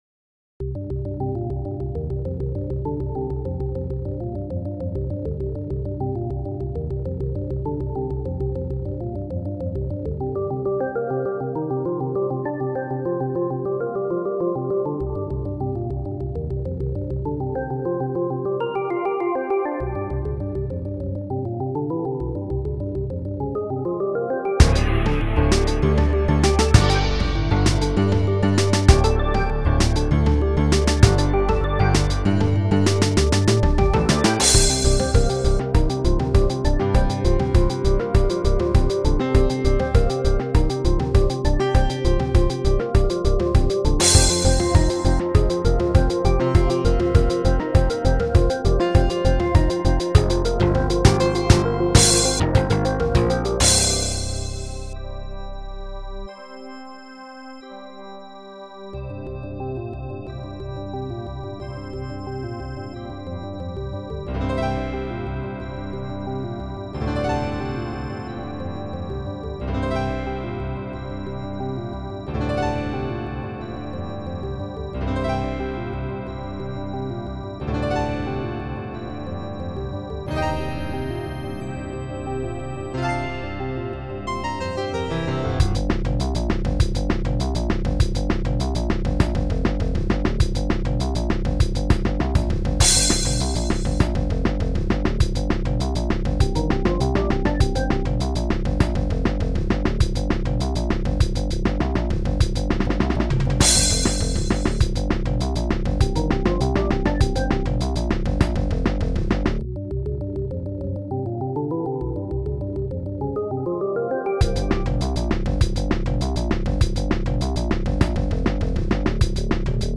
On the other hand, a believable rock organ is not too hard.  Here is another Csound composition I wrote featuring an attempt at instrumental progressive rock.